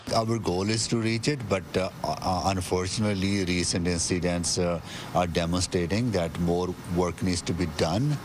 Edmonton Mayor Amarjeet Sohi agrees that there is still much work needed in this area: